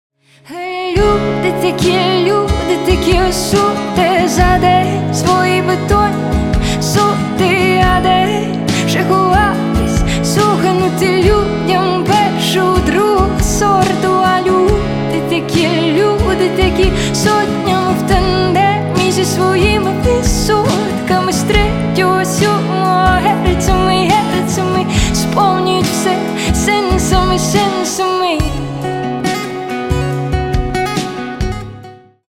• Качество: 320 kbps, Stereo
Поп Музыка
тихие
спокойные